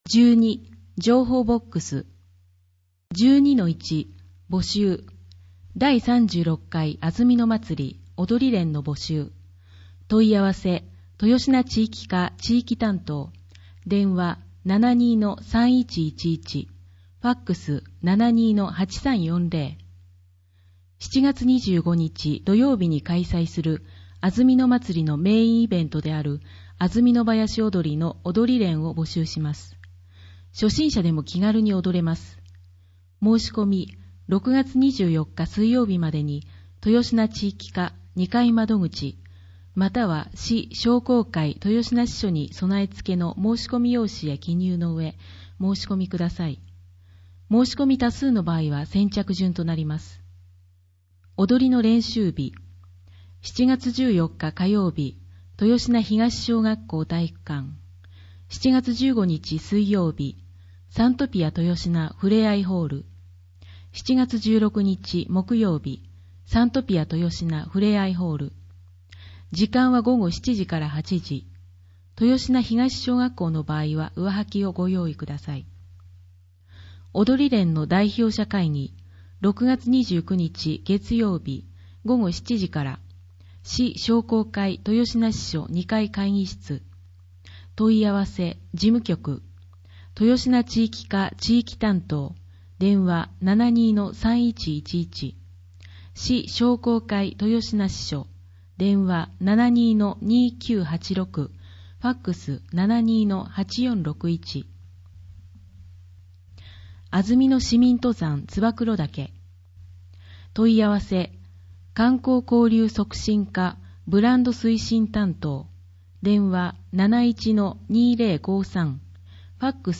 広報あづみの朗読版209号（平成27年6月17日発行) - 安曇野市公式ホームページ
「広報あづみの」を音声でご利用いただけます。この録音図書は、安曇野市中央図書館が制作しています。